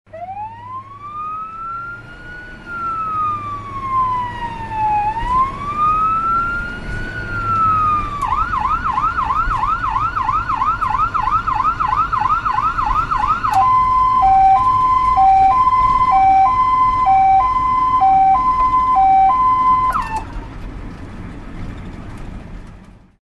На этой странице вы можете скачать и послушать онлайн различные звуки сирены скорой помощи.
Звук сирены скорой помощи изнутри салона автомобиля